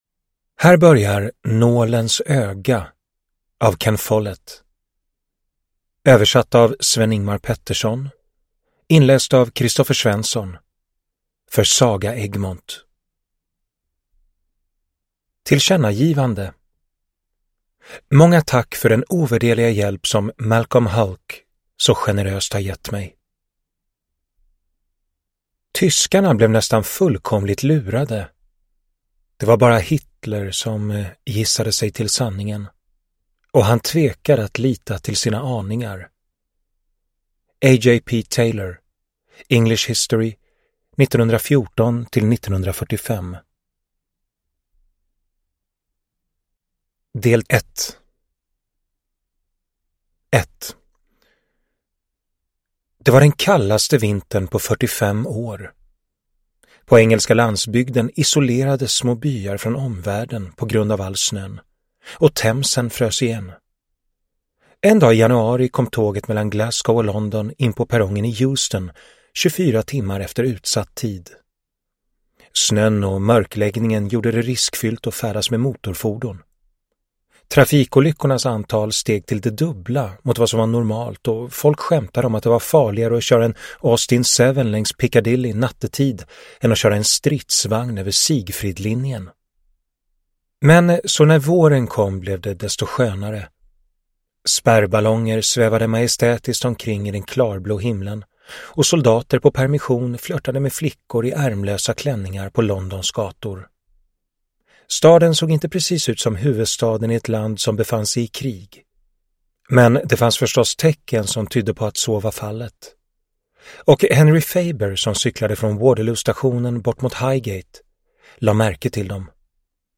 Nålens öga (ljudbok) av Ken Follett